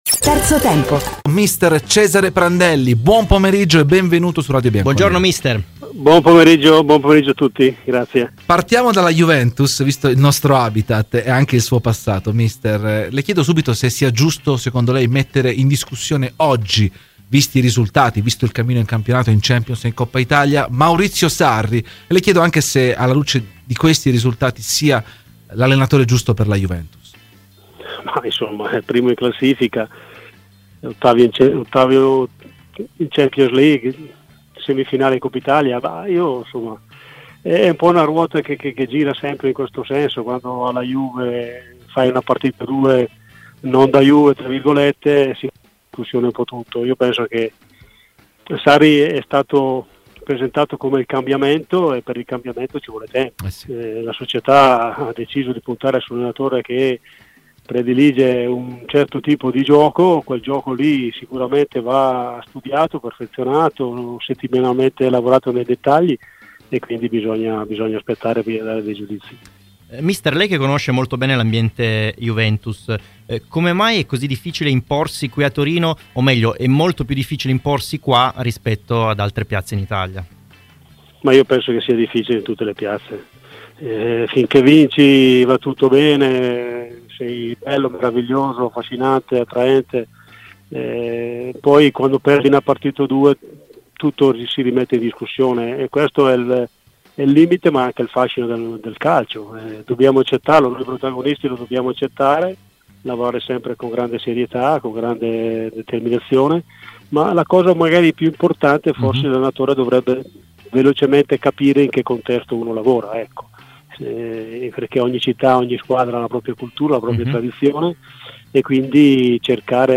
L’ex ct della Nazionale italiana Cesare Prandelli ha parlato oggi ai microfoni di Radio Bianconera, nel corso di ‘Terzo Tempo’: “Giusto mettere in discussione Sarri? È primo in classifica, agli ottavi di Champions e in semifinale di Coppa Italia. È una ruota che gira, quando fai una partita non da Juve si rimette in discussione tutto. Sarri è stato presentato come il cambiamento, ma per il cambiamento serve tempo. Quel tipo di gioco va elaborato nei dettagli, quindi bisogna aspettare”.